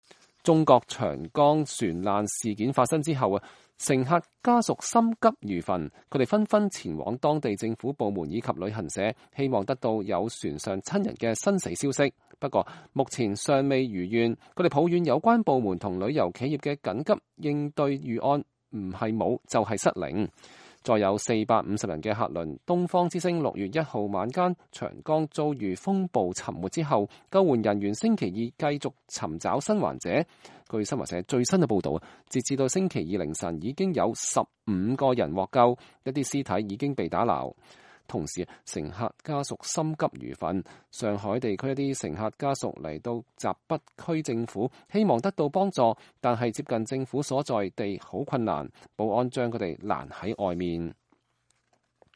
進去的人也無人接待，家屬在大廳內和保安發生口角，可以聽見他們對官方接待很不滿意。